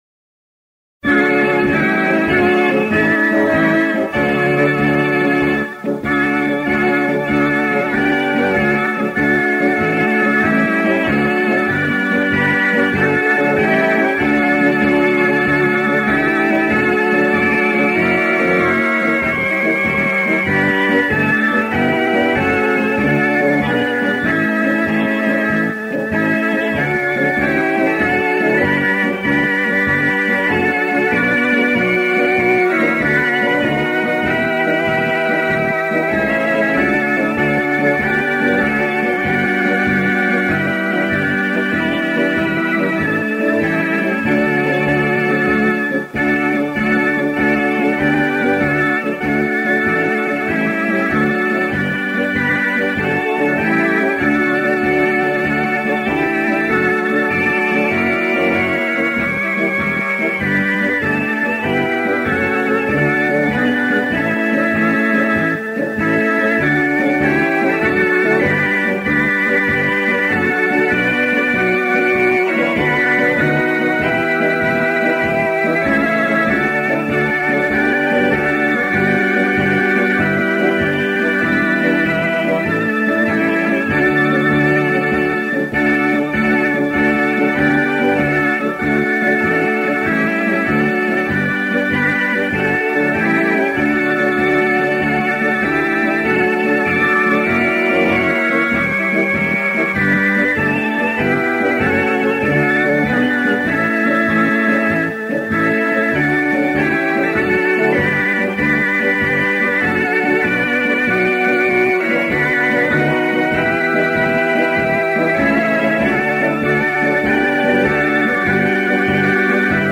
Accompaniment
[This accompaniment includes a prelude]
Meter: 7.6.7.6.D.